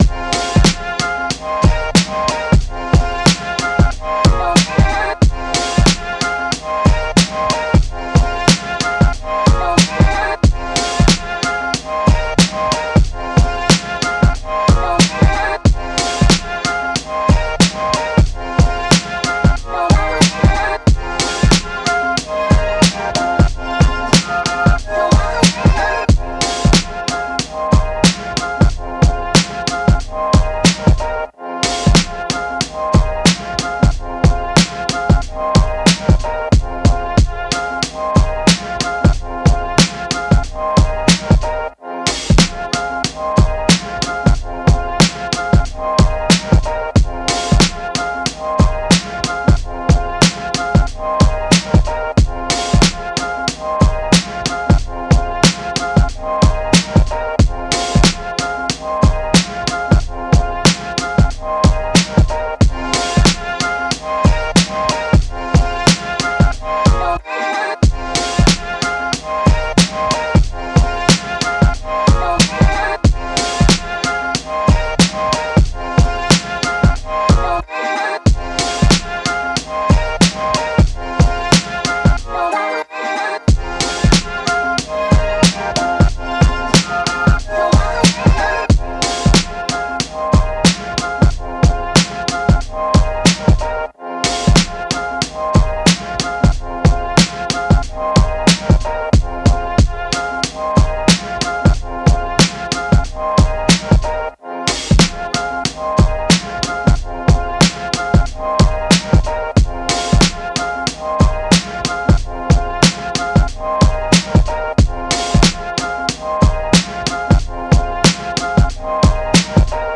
Hip Hop, 90s
Fmin